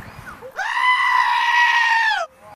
CRAZY GOAT SCREAM - Free Meme Sound
CRAZY GOAT SCREAM